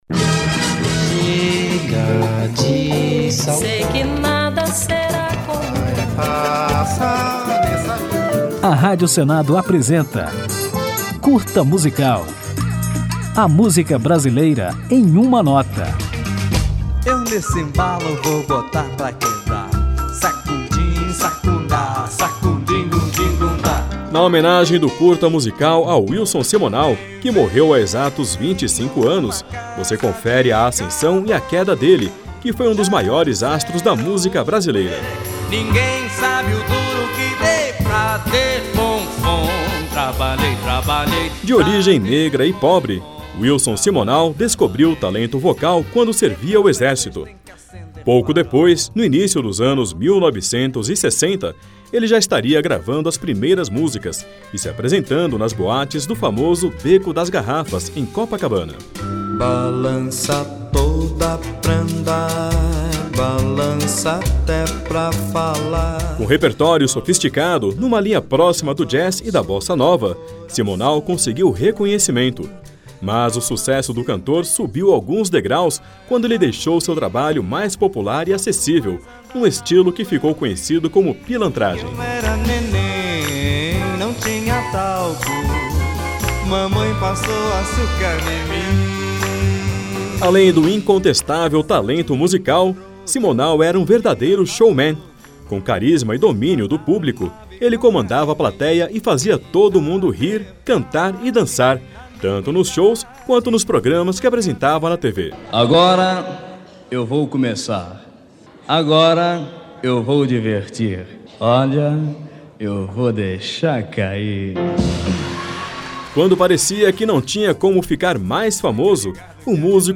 Depois de analisado os altos e baixos do Rei da Pilantragem, ouviremos a música Carango, sucesso de Wilson Simonal, de 1966.